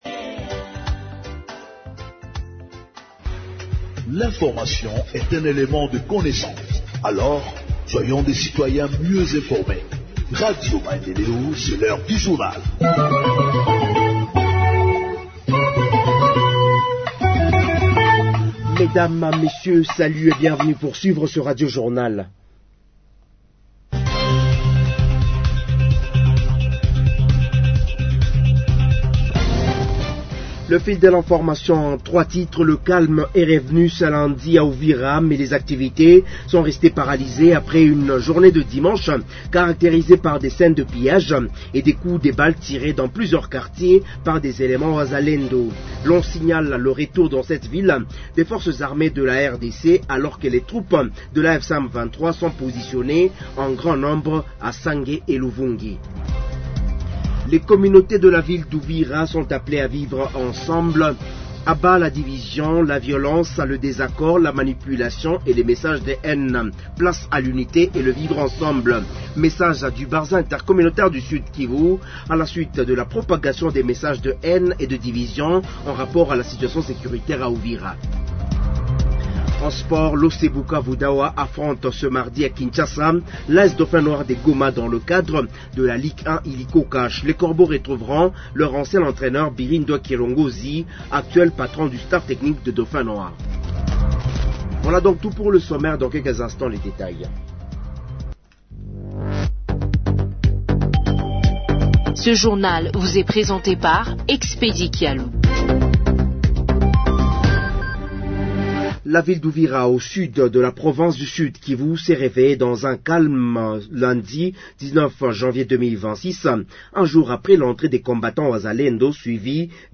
Journal en Français du 20 Janvier 2026 – Radio Maendeleo